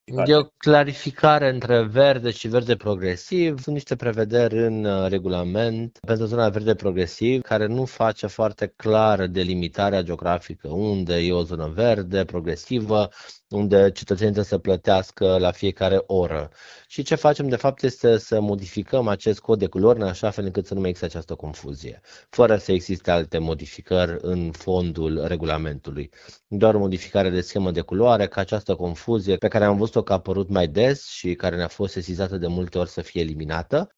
În ședința de astăzi, viceprimarul Ruben Lațcău a explicat că s-a făcut și o modificare menită să îi ajute pe șoferi să diferențieze mai bine două zone de parcare din centru.